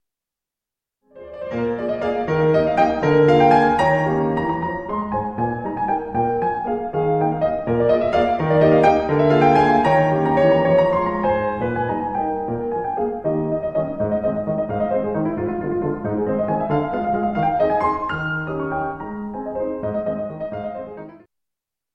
piano.mp3